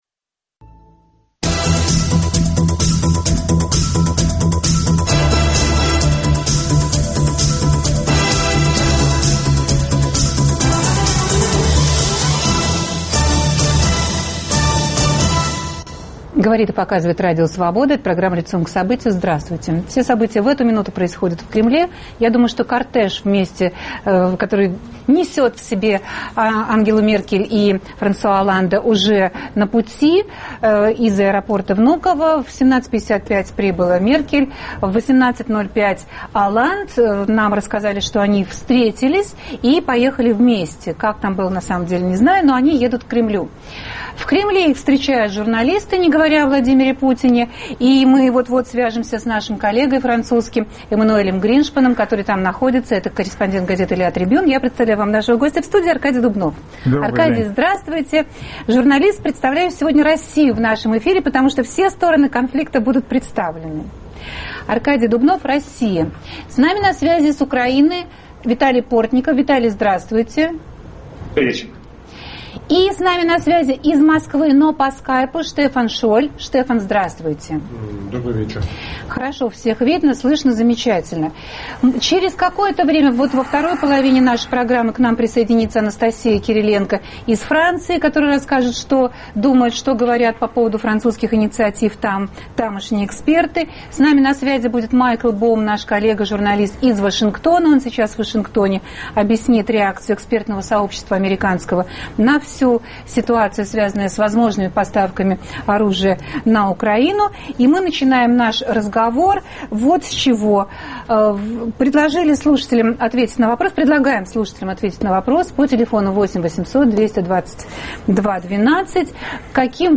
Обсуждаем в прямом эфире.